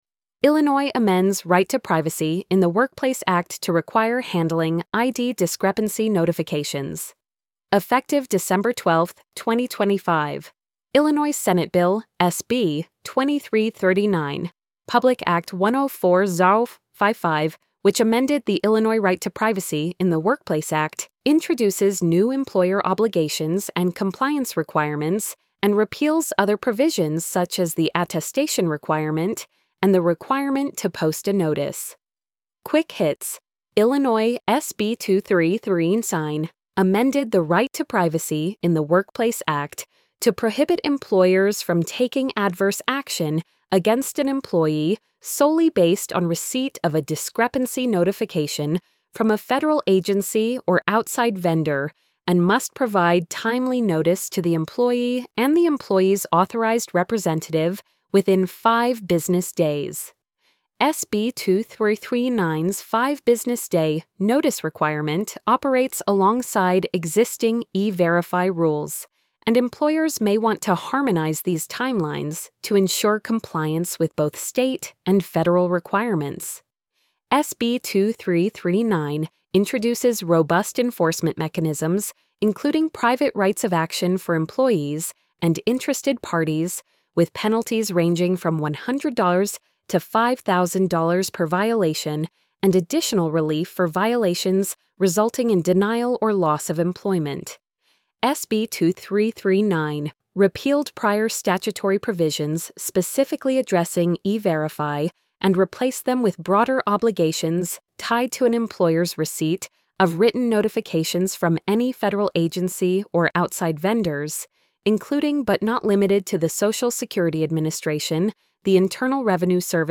illinois-amends-right-to-privacy-in-the-workplace-act-to-require-handling-id-discrepancy-notifications-tts-2.mp3